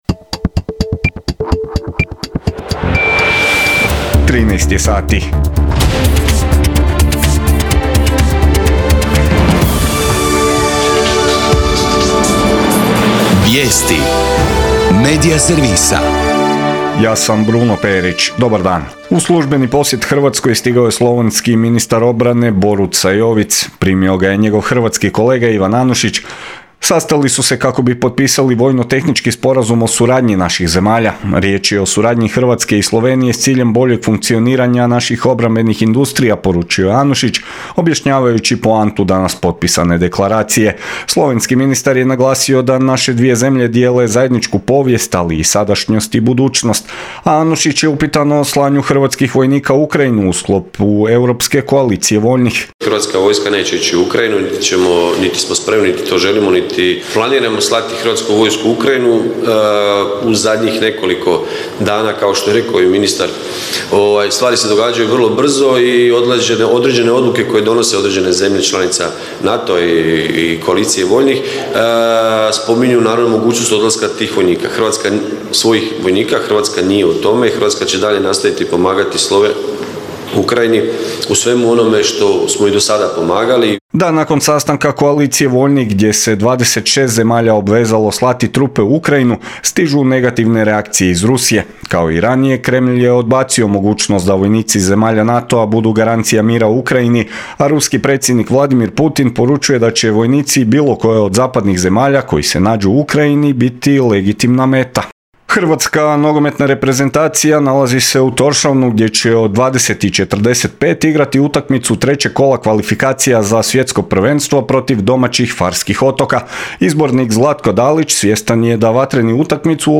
VIJESTI U 13